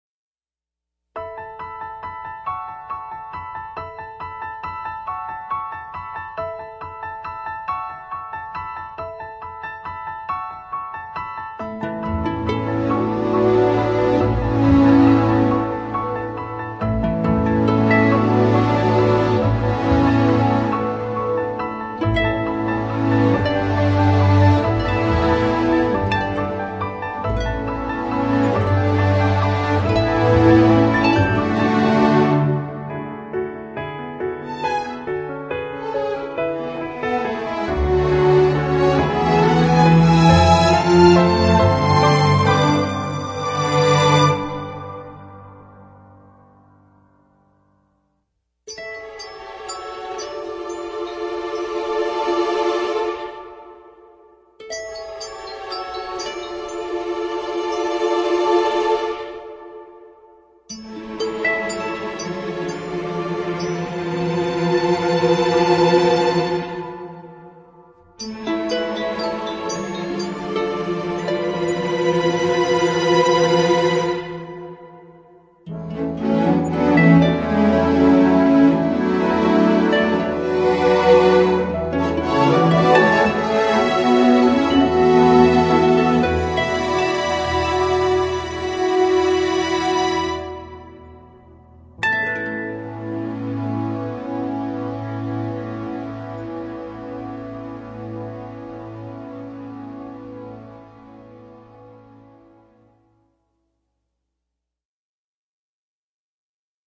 Instrumental with NO COMPOSER mentioned!!!!